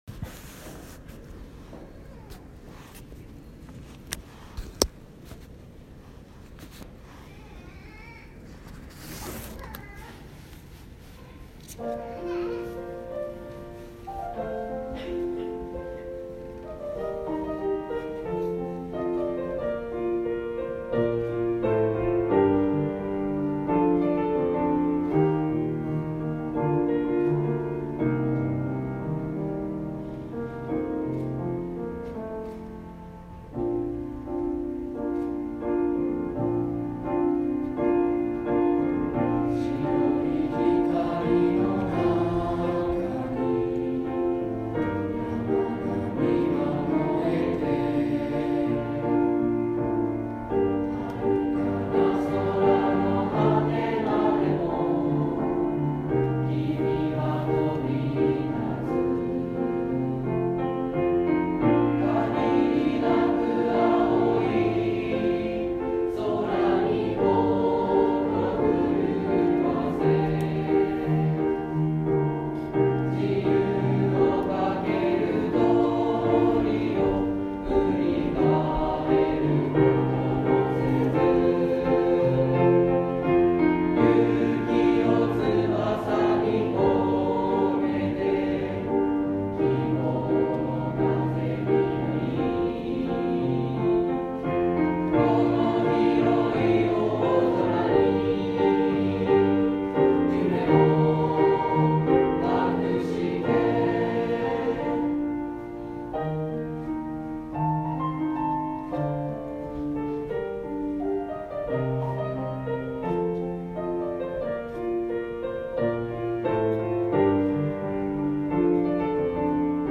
この春に卒業した59期生の一生懸命の歌声は以下をクリックしてください。